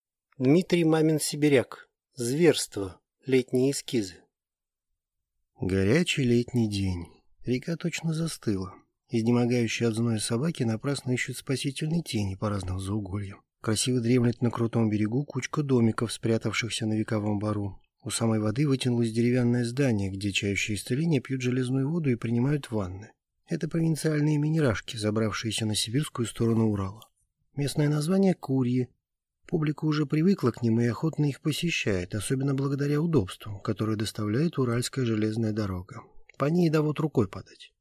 Aудиокнига Зверство